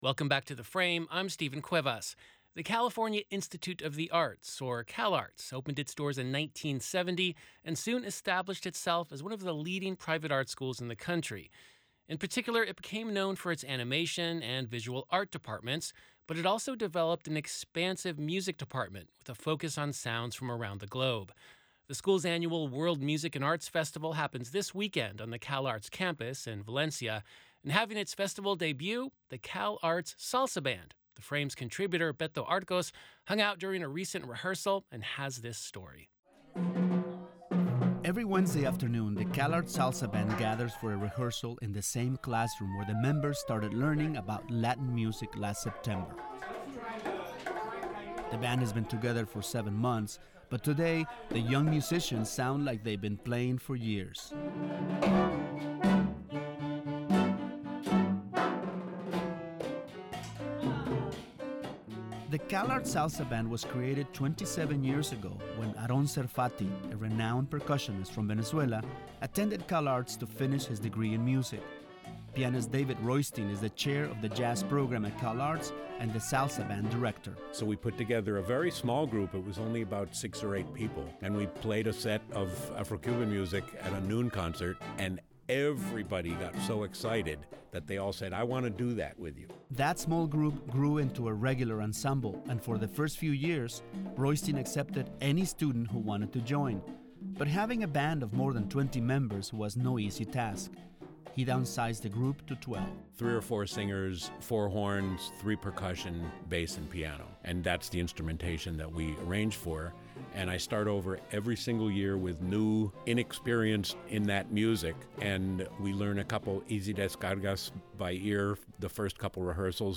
Every Wednesday afternoon, the band gathers for a rehearsal, in the same classroom where the members began learning about Latin music last September. The band was formed only seven months ago, but the young musicians sound like they’ve been playing together for years.